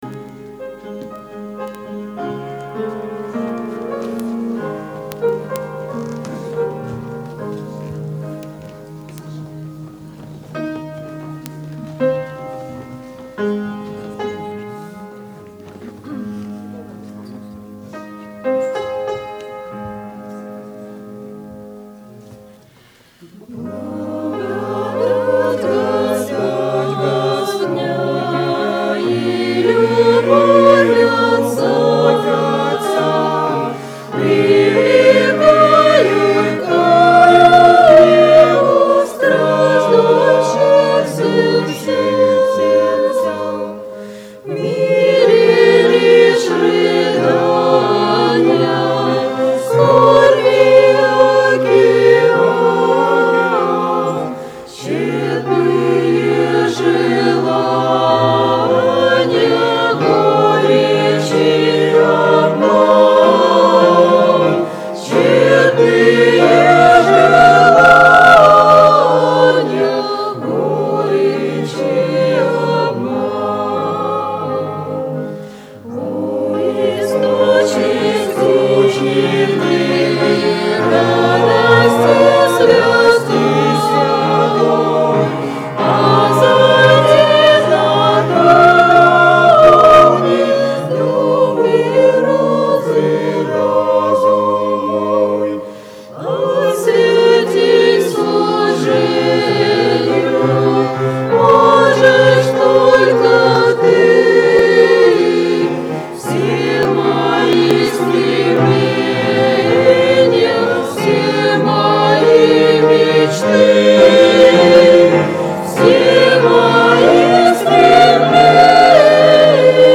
Хор г. Астана on 2014-06-14 - Христианские песни